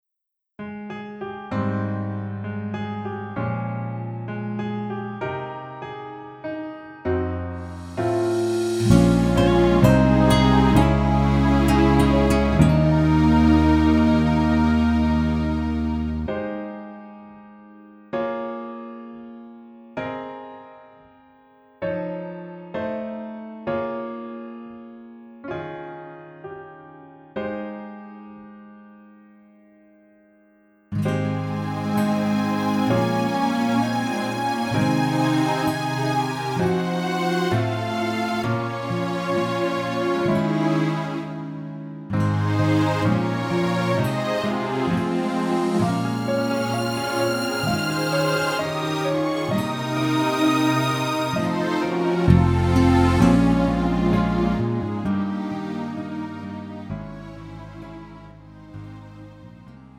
음정 -1키 3:34
장르 가요 구분 Pro MR
Pro MR은 공연, 축가, 전문 커버 등에 적합한 고음질 반주입니다.